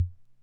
Conn Min-O-Matic Rhythm Sample Pack_Kick.wav